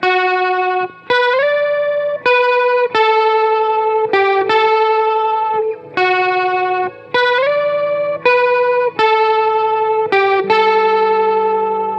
Another Crunchy Guitar Loop
标签： 80 bpm Rock Loops Guitar Electric Loops 2.02 MB wav Key : A
声道立体声